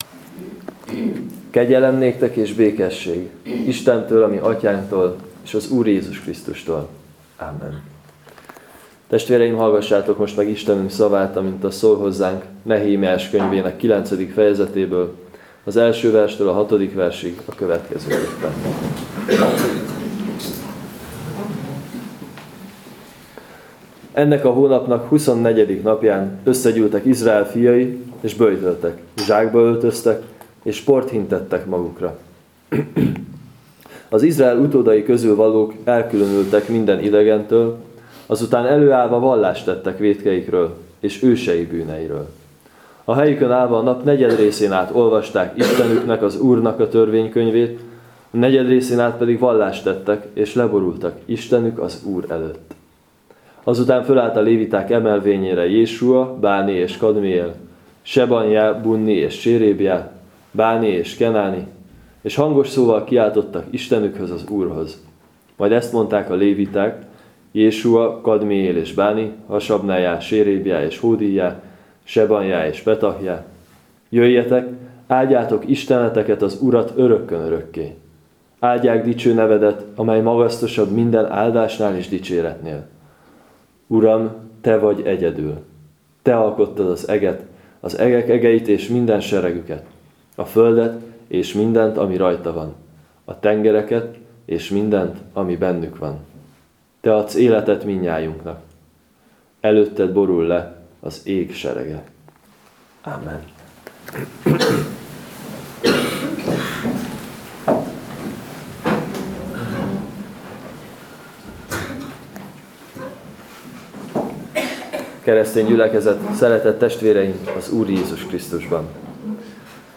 03.05. Neh 9,1-6 igehirdetés.mp3 — MP3 audio, 25903Kb